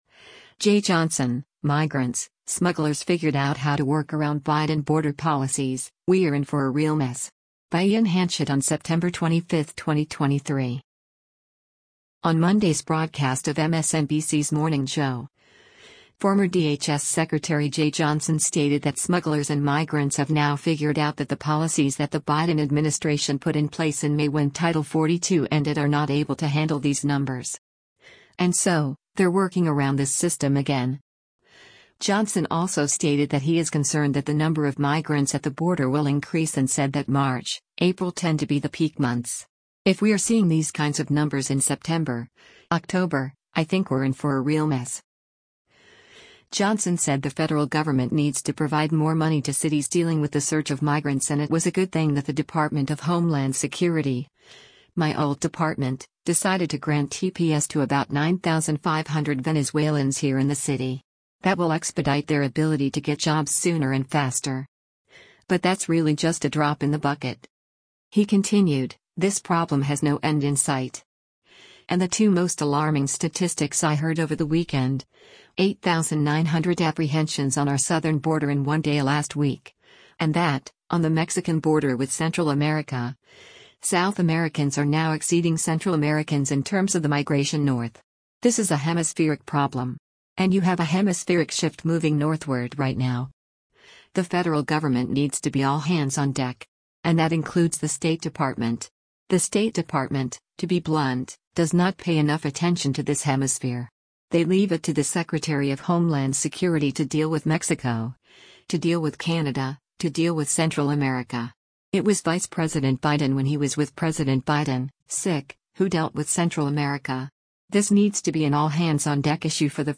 On Monday’s broadcast of MSNBC’s “Morning Joe,” former DHS Secretary Jeh Johnson stated that smugglers and migrants “have now figured out” that the policies that the Biden administration put in place in May when Title 42 ended “are not able to handle these numbers. And so, they’re working around this system again.”